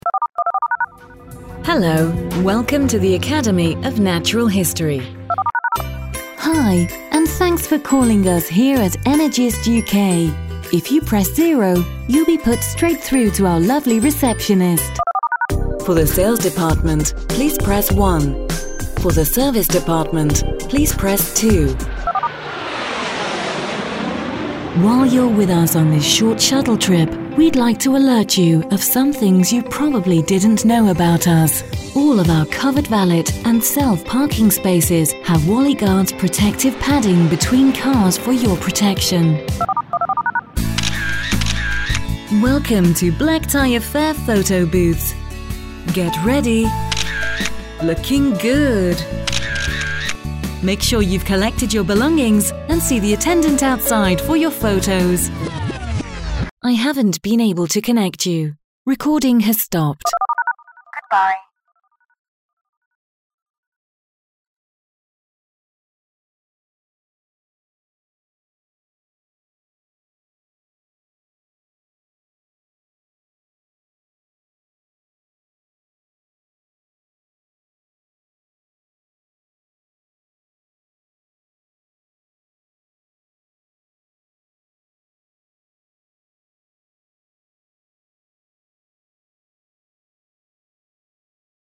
Experienced versatile British female voice over.
Warm, soothing, luxurious to fun, bright and bubbly.
Sprechprobe: Sonstiges (Muttersprache):